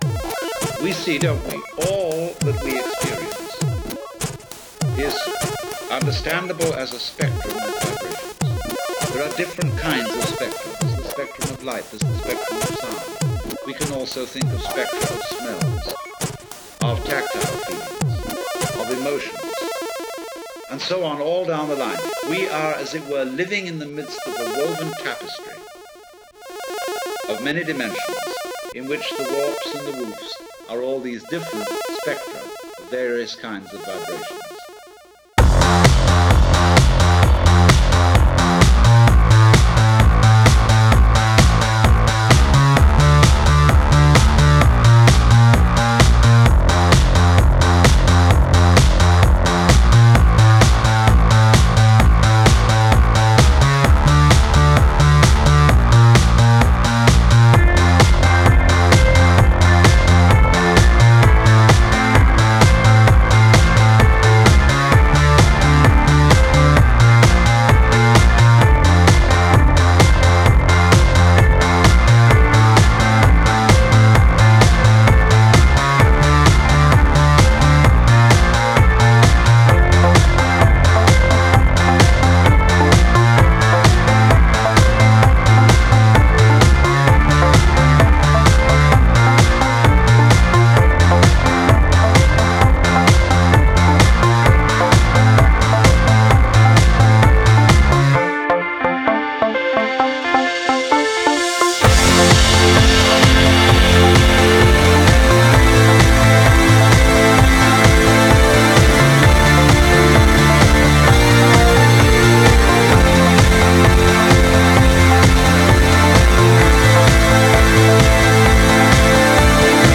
Brisk and more dance than indie.